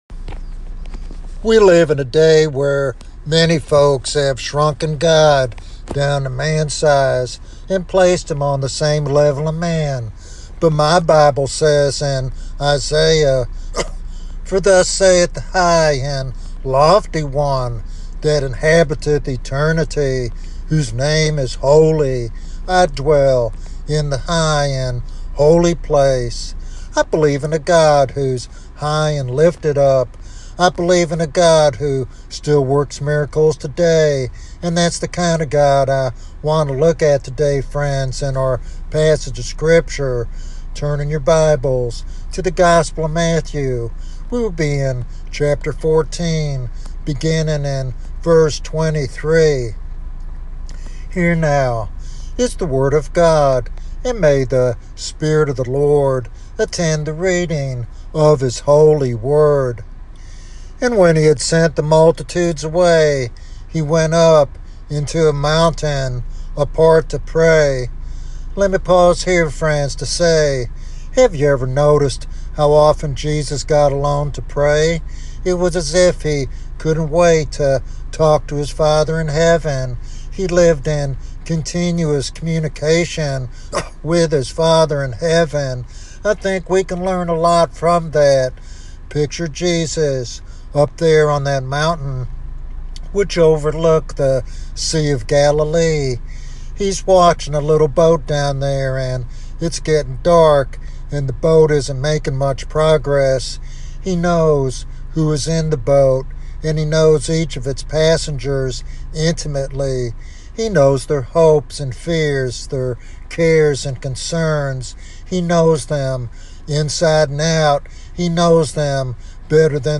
This devotional sermon encourages believers to expect God to move supernaturally in their lives and to live boldly for His glory.